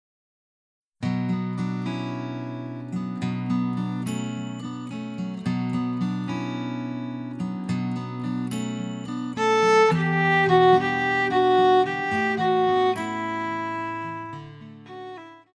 流行
中提琴
乐团
演奏曲
世界音乐,融合音乐
仅伴奏
没有主奏
没有节拍器
曲子附有演奏版和不含主旋律的伴奏版。